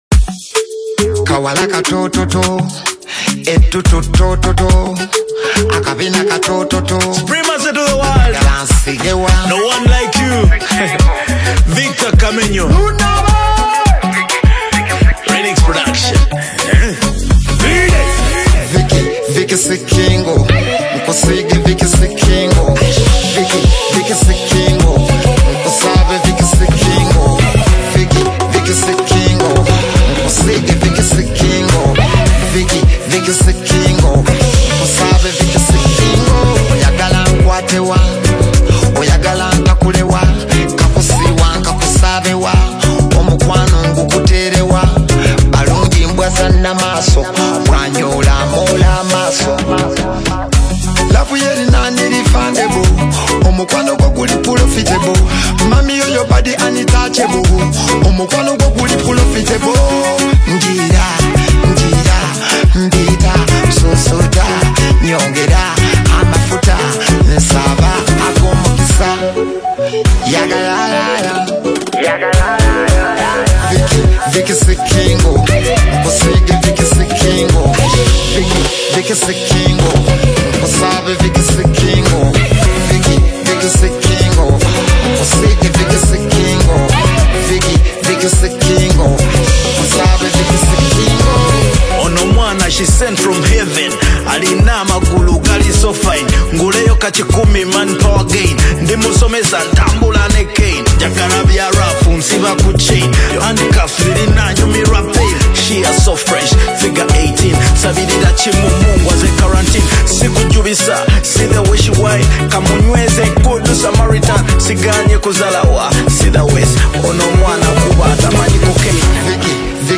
Latest Uganda Afro-Beats Single (2026)
blending catchy melodies with meaningful songwriting.
Genre: Afro-Beats